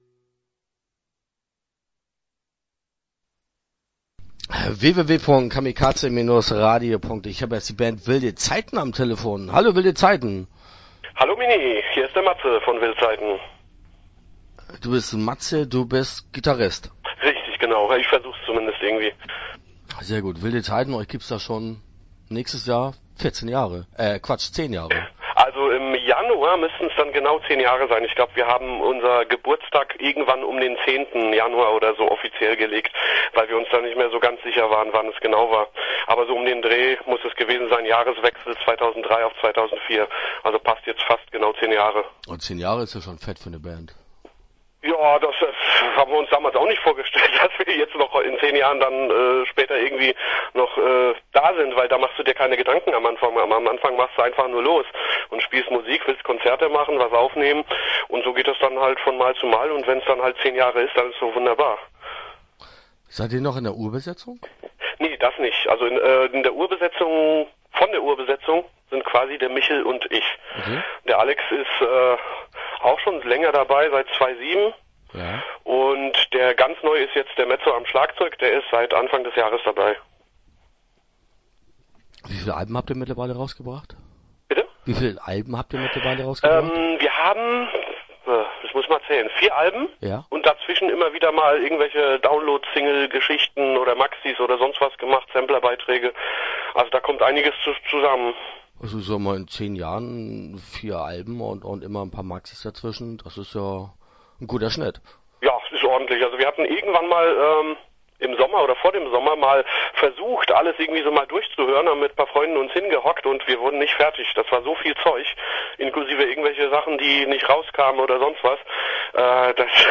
Interview Teil 1 (14:59)